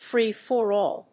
/ˈfrifɔˌrɔl(米国英語), ˈfri:fɔ:ˌrɔ:l(英国英語)/
アクセントfrée‐for‐àll